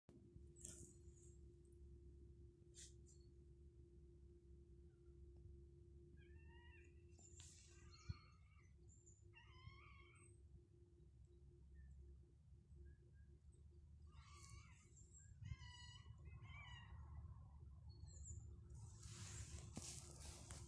Middle Spotted Woodpecker, Leiopicus medius
Ziņotāja saglabāts vietas nosaukumsVecumnieku pag, Valle
StatusVoice, calls heard